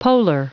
Prononciation du mot polar en anglais (fichier audio)